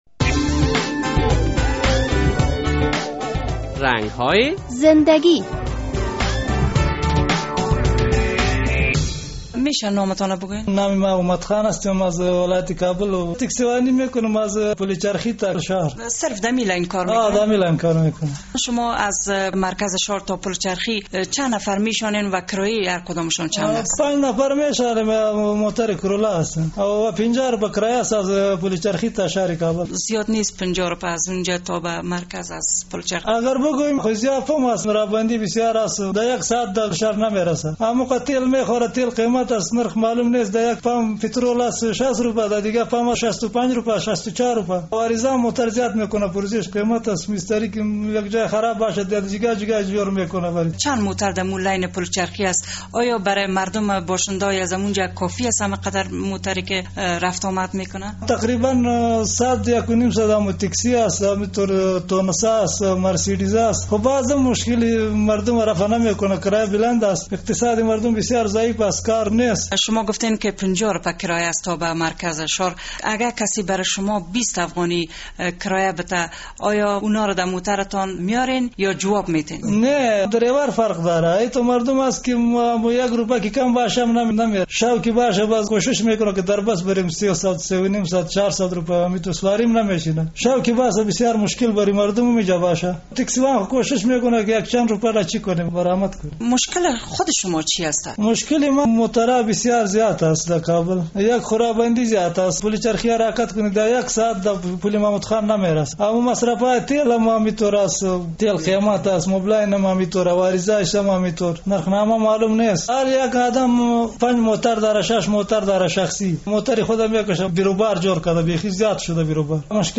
در این برنامهء رنگ های زندگی خبرنگار رادیو آزادی با یک تن از راننده های تکسی در شهر کابل صحبت کرده و از وی در مورد کار و بارش پرسیده است.